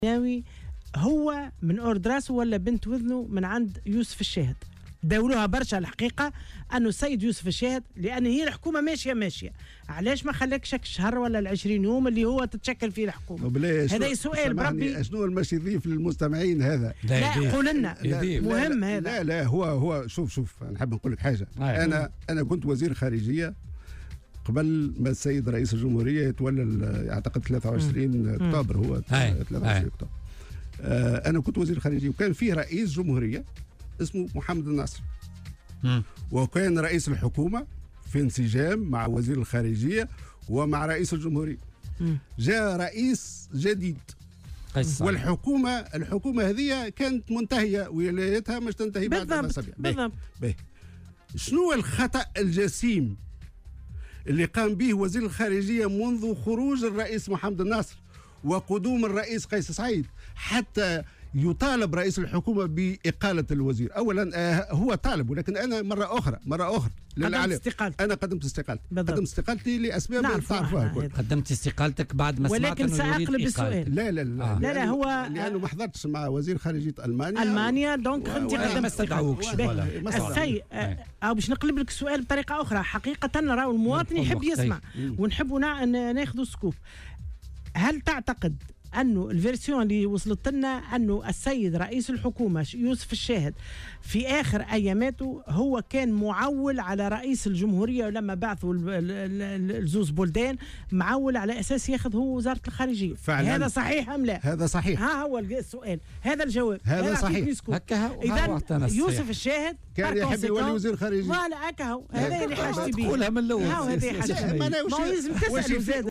وكشف ضيف بوليتيكا على "الجوهرة أف أم" انه لم يتم قبوله من طرف قيس سعيّد ابان ترؤسه الحقيبة الدبلوماسية في تونس.